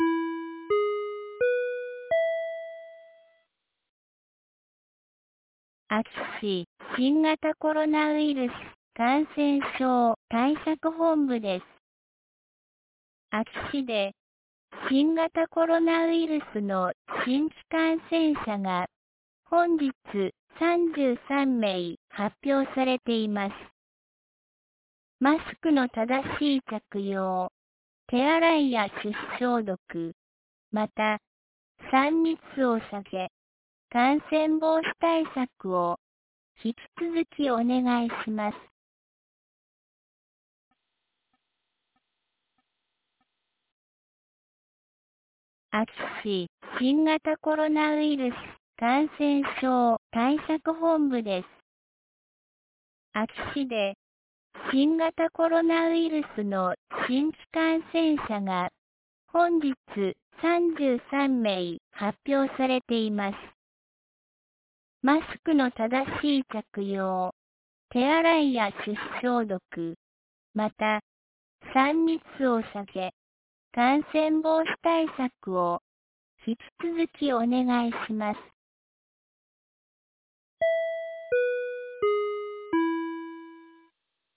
2022年08月23日 17時06分に、安芸市より全地区へ放送がありました。